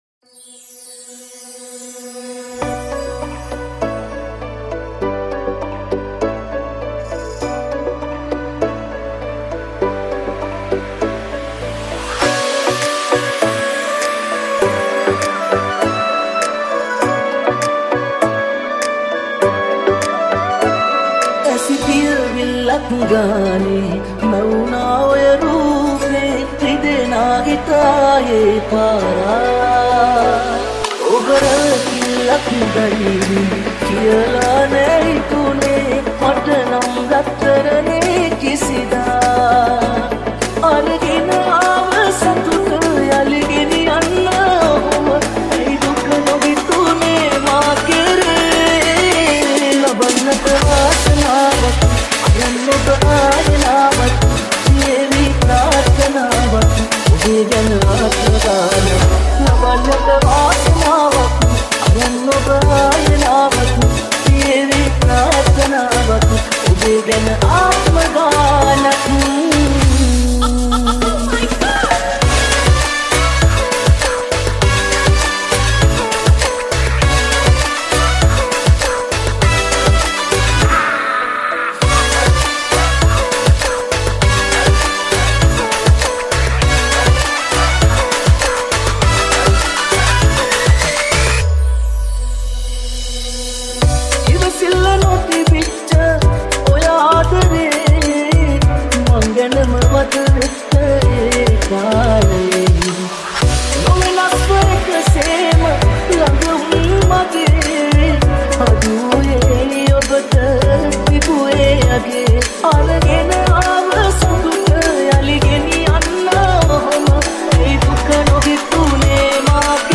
Sinhala New Remix 2020 | Dj Remix 2020